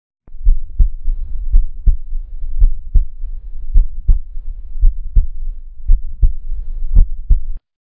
心臟收縮和舒張時，會發出撲通撲通聲，就像搖晃一隻裝滿水的水壺，會發出聲響，屬於正常心音。
normal_volume_up.mp3